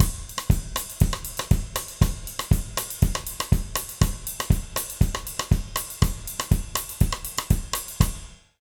120ZOUK 08-R.wav